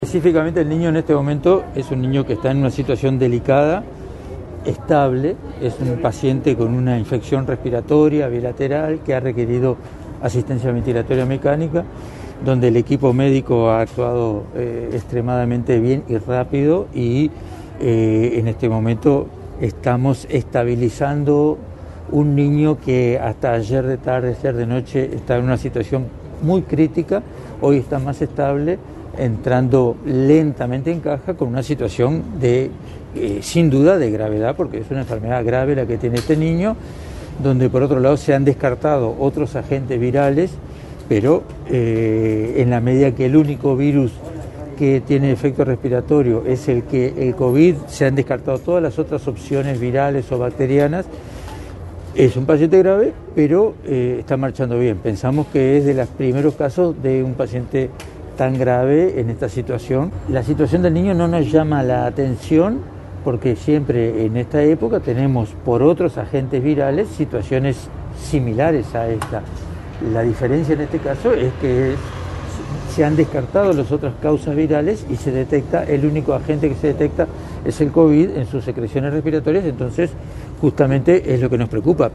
en conferencia de prensa.